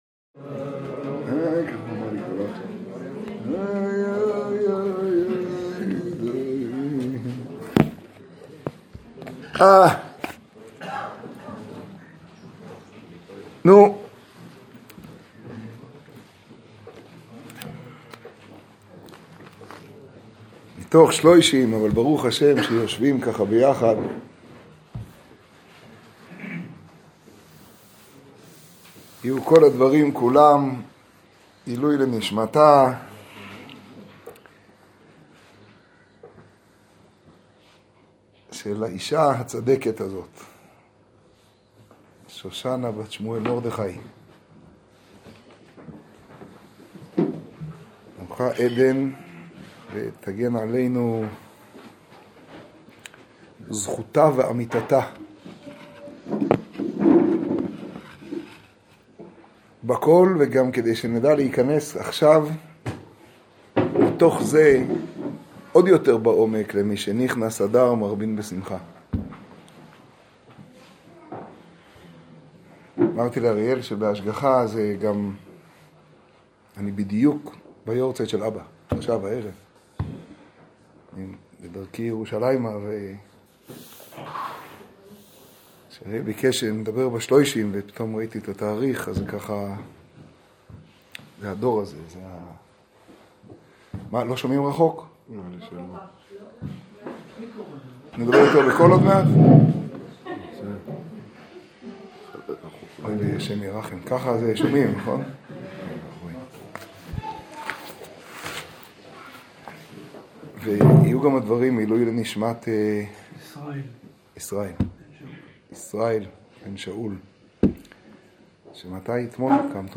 השיעור בפרדס חנה, פרשת משפטים תשעז.